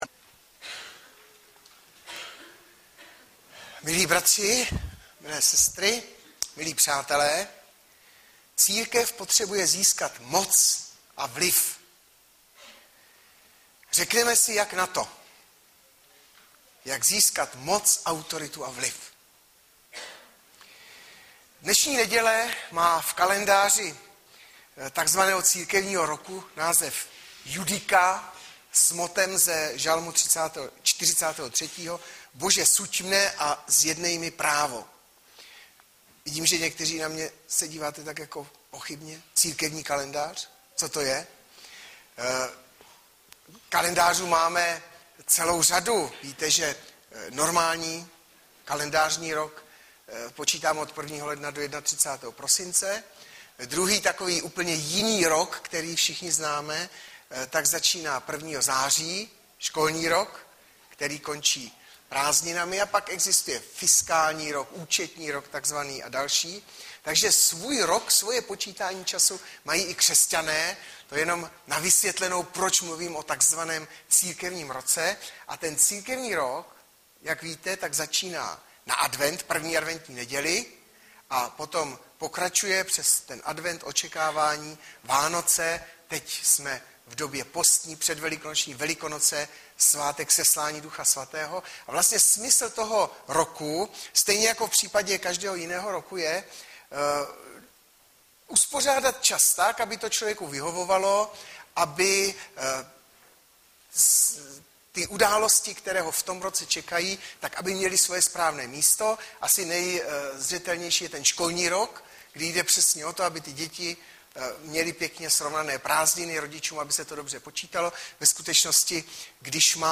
Hlavní nabídka Kázání Chvály Kalendář Knihovna Kontakt Pro přihlášené O nás Partneři Zpravodaj Přihlásit se Zavřít Jméno Heslo Pamatuj si mě  17.03.2013 - JAK ZÍSKAT MOC A VLIV - Mat 18,15-20 Audiozáznam kázání si můžete také uložit do PC na tomto odkazu.